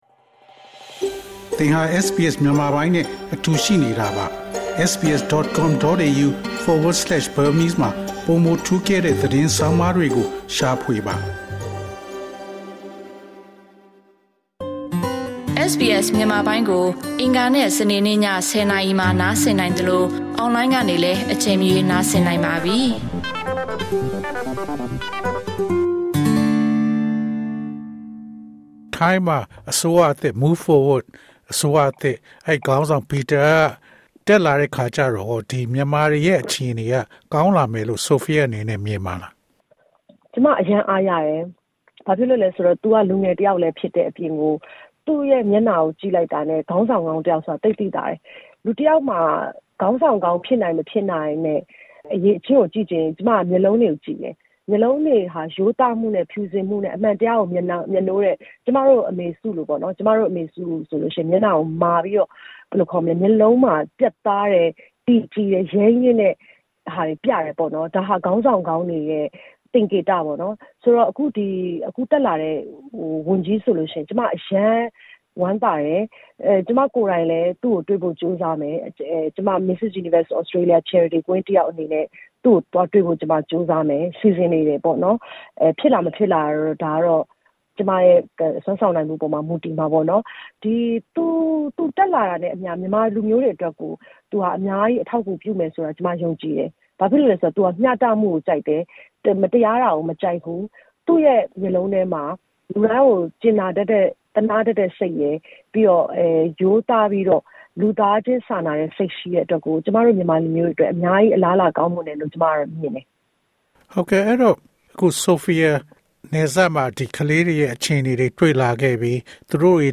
အင်တာဗျူး